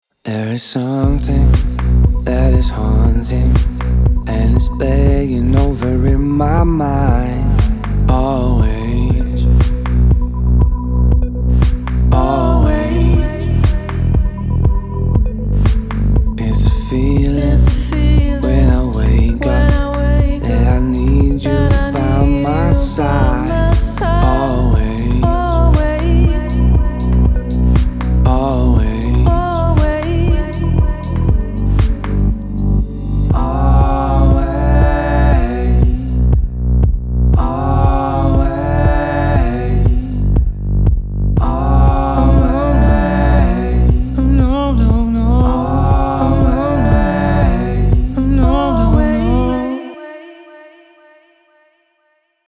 Эмуляция студии звукозаписи 1981-1984 годов
Давайте забудем о них, возьмем такое демо в работу в нашей виртуальной студии Только сначала для этого демо допишем аранжировку в духе 81 года. Вложения always_81_demo_Bm_119bpm.mp3 always_81_demo_Bm_119bpm.mp3 2 MB · Просмотры: 7.698